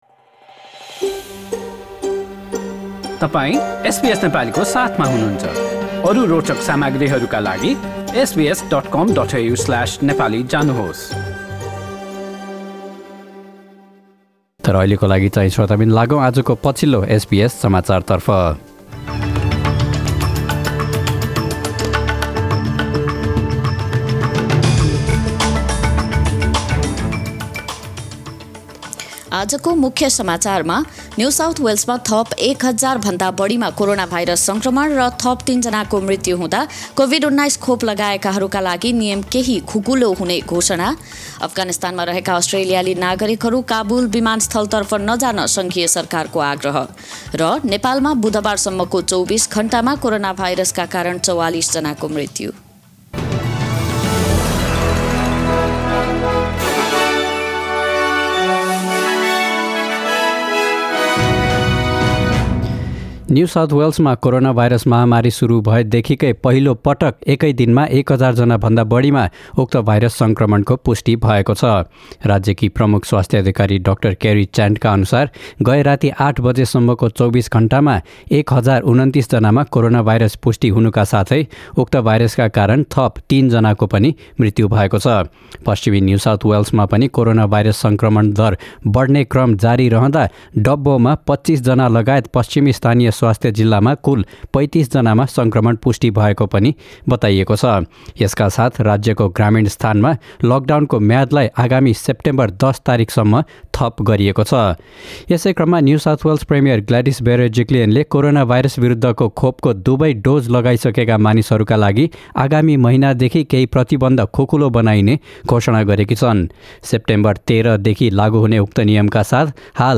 Listen to the latest news headlines from Australia in Nepali.